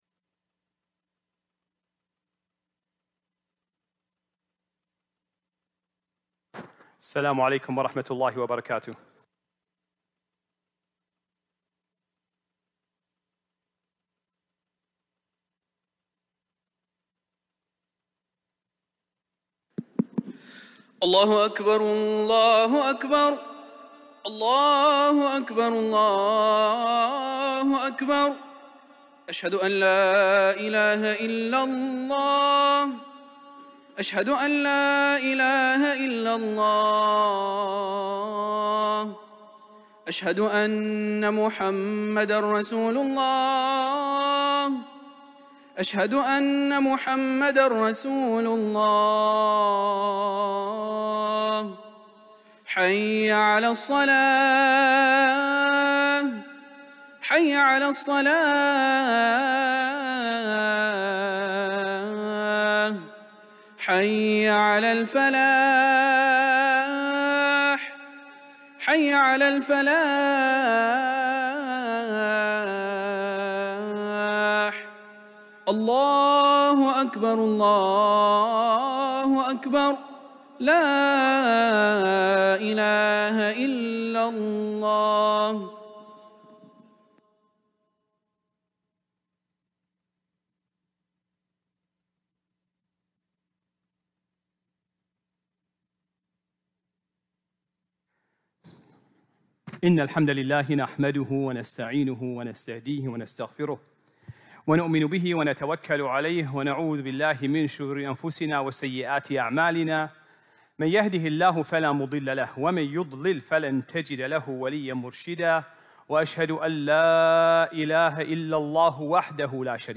Friday Khutbah - "Small Blessings"